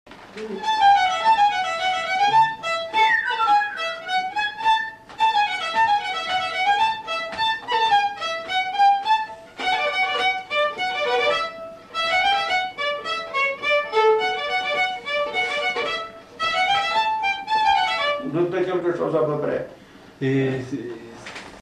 Congo